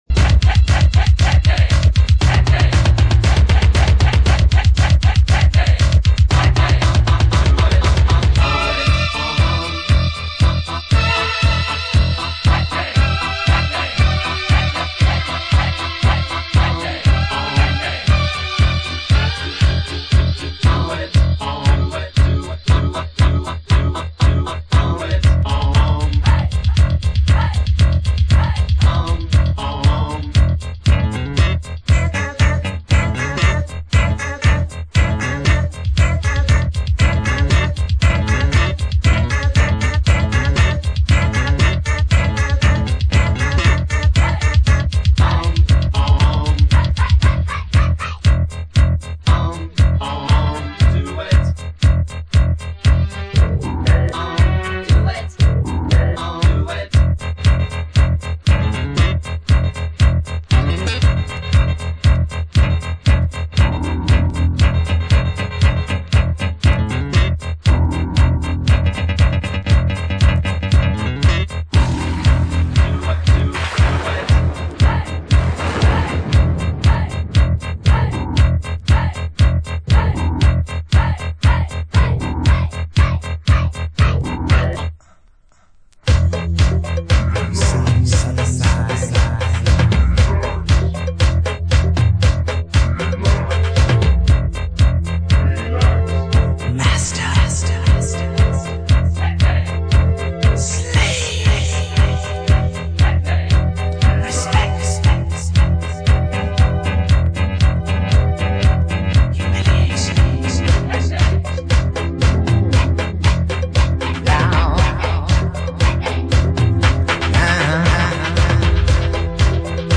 Special Re-Edit